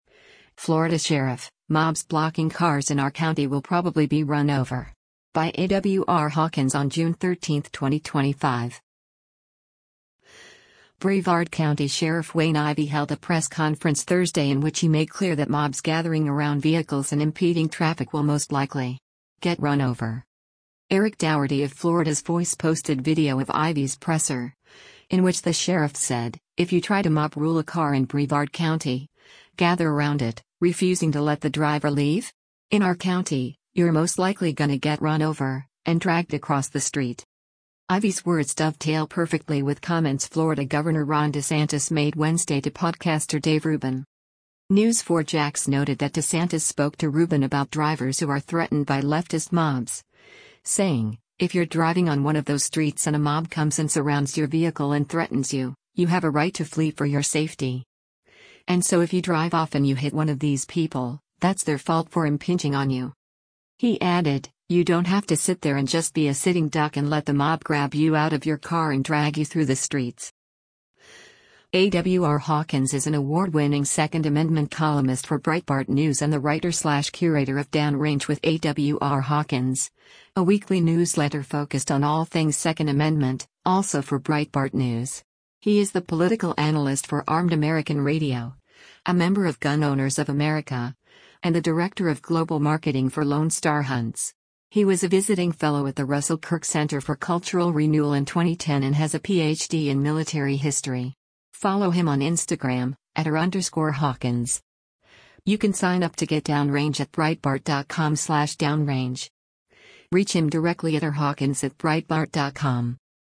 Brevard County Sheriff Wayne Ivey held a press conference Thursday in which he made clear that mobs gathering around vehicles and impeding traffic will “most likely… get run over.”